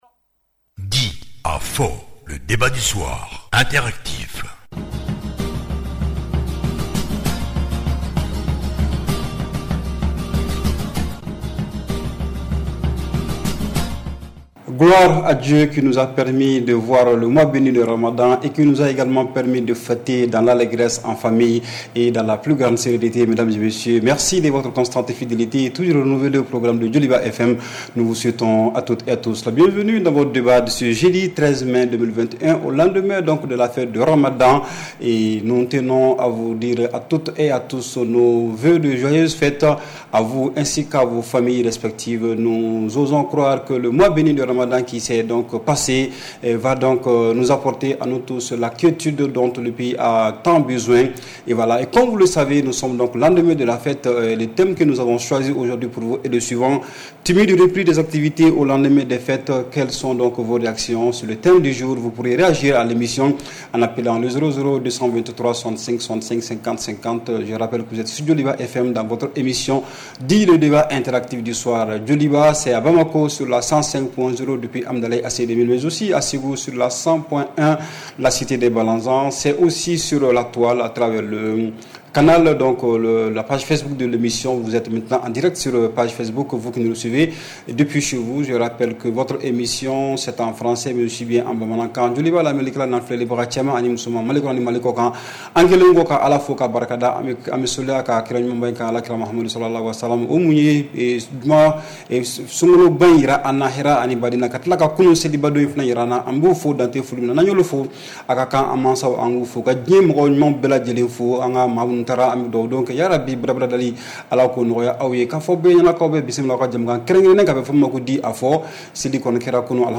REPLAY 13/05 – « DIS ! » Le Débat Interactif du Soir